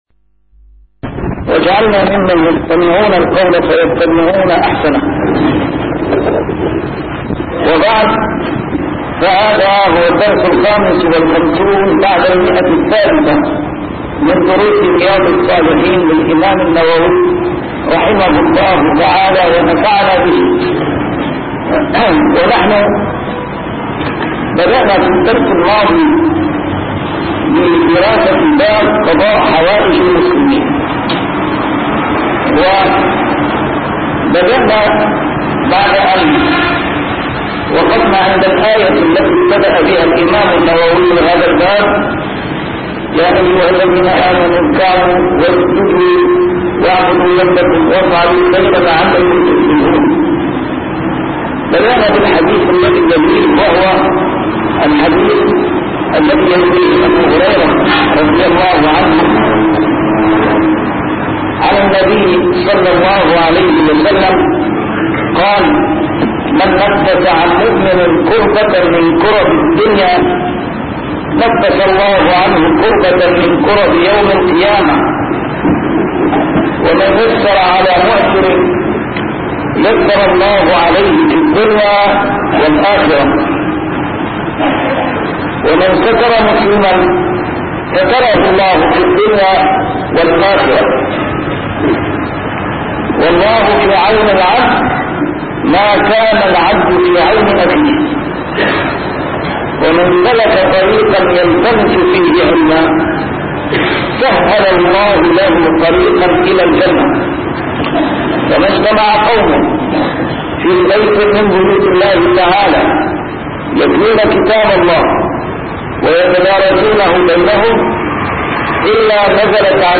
A MARTYR SCHOLAR: IMAM MUHAMMAD SAEED RAMADAN AL-BOUTI - الدروس العلمية - شرح كتاب رياض الصالحين - 355- شرح رياض الصالحين: قضاء حوائج المسلمين